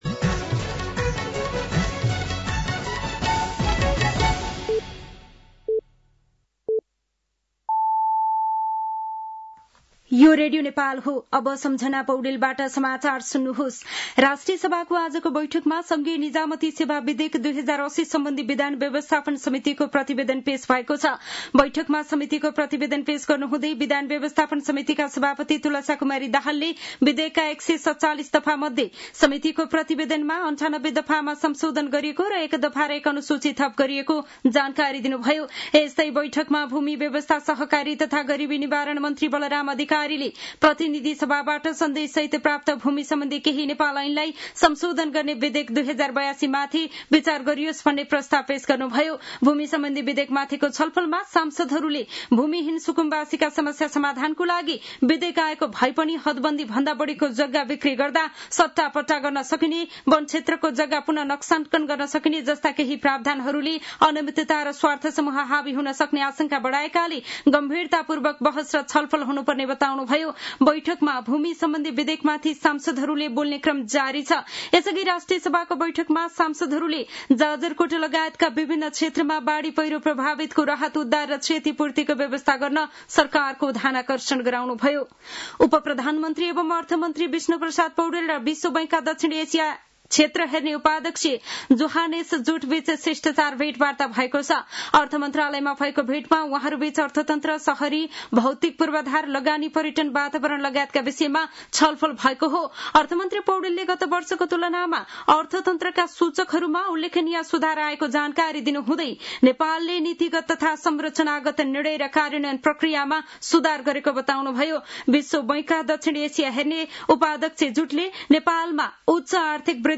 साँझ ५ बजेको नेपाली समाचार : १७ भदौ , २०८२
5-pm-news-5-17.mp3